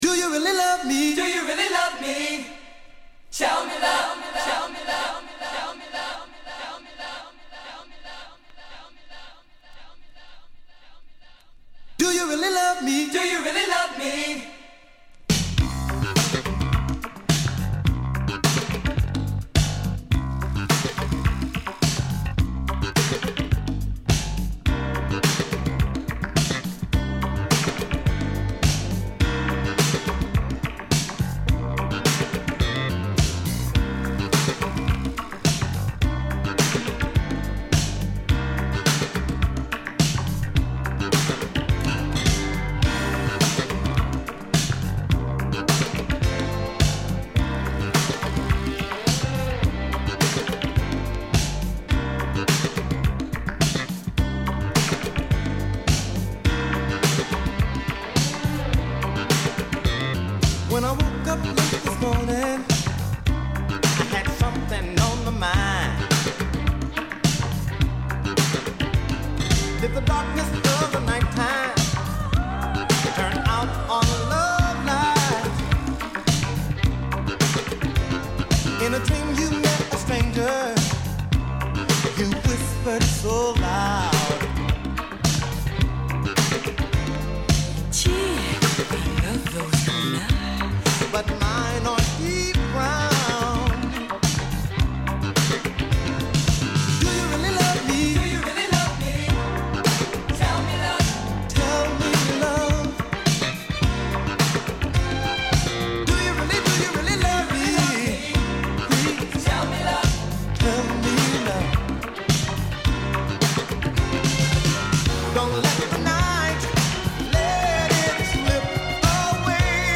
グルーヴィSOUL DISCO
体を心地良く揺らすリズムに伸びやかなヴォーカルが絡む